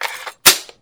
Reload.wav